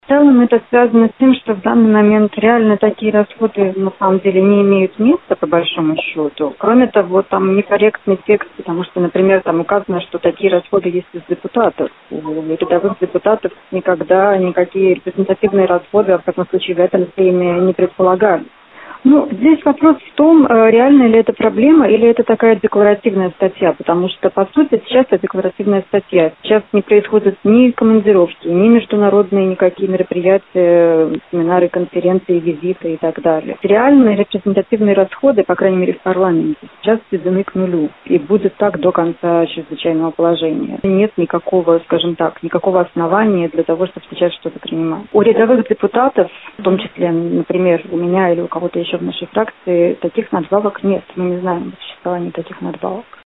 Большинство депутатов Сейма не поддержали предложение оппозиции на время кризиса отказаться от так называемых репрезентативных расходов, поскольку в условиях чрезвычайной ситуации средства на визиты, приемы и прочие мероприятия в парламенте и так не выделяются. Об этом радио Baltkom сообщила депутат от объединения «Для развития/За!» Мария Голубева.